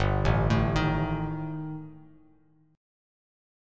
Gsus2#5 chord